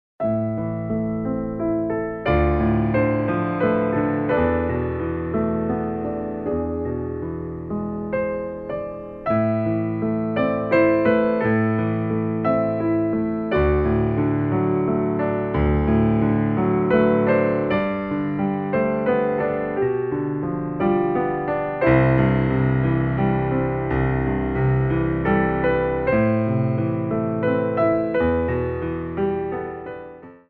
Ports de Bras / Révérance
12/8 (8x8)